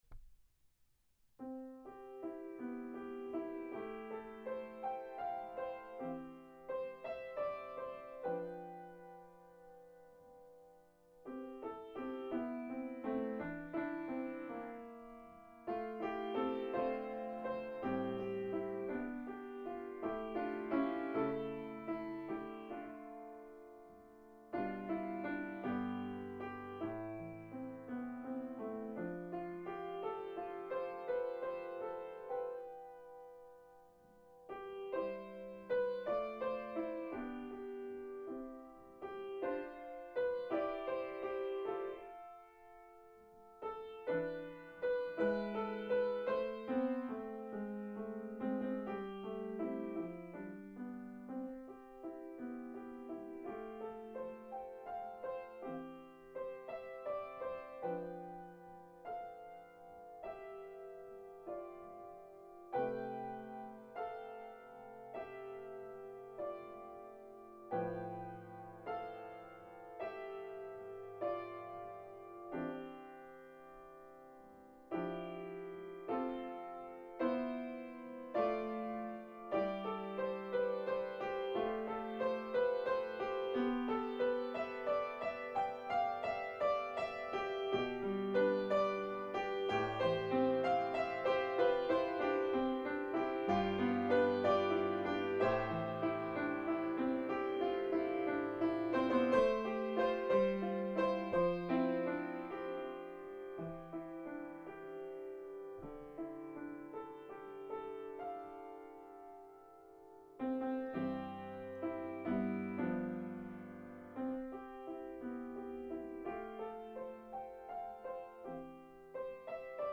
So, today I offer a song about singing.  The words to this gospel hymn were written by Civilla D. Martin in 1905 and are said to have been inspired by one of her close friendships.